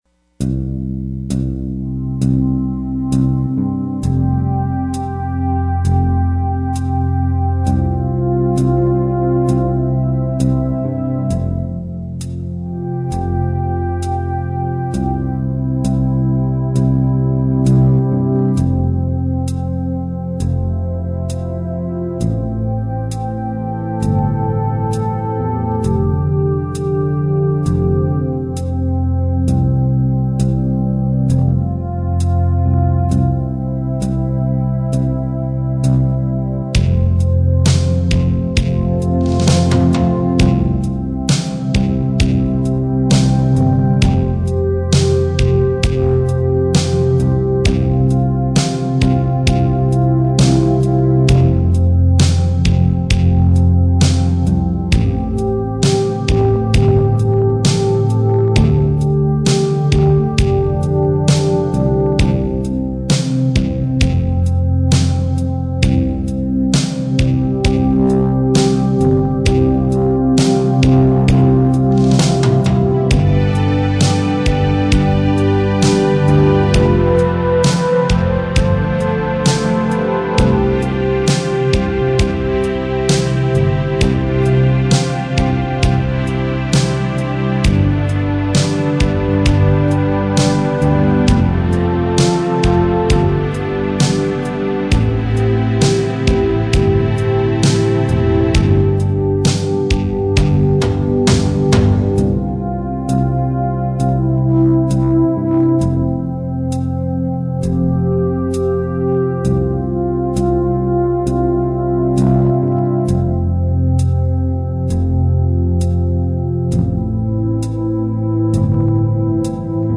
We Tremble In the Presence of the Lord-Instrumental
We-Tremble-In-the-Presence-of-the-Lord-Instrumental.mp3